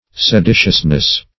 Se*di"tious*ness, n.